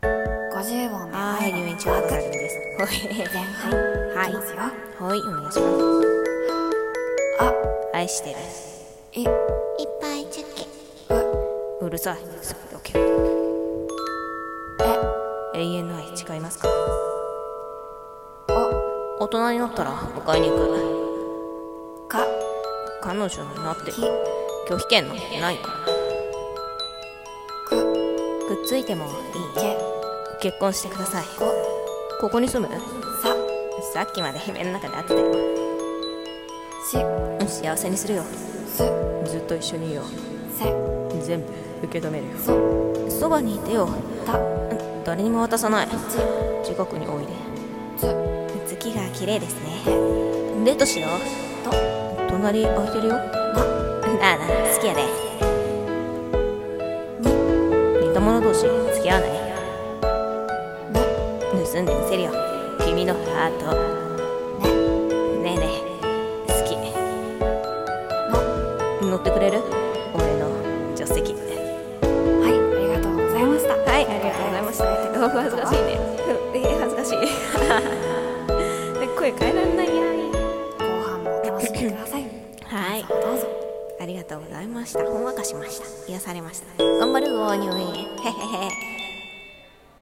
[声面接] 50音で愛の告白 [演技力]